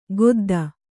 ♪ godda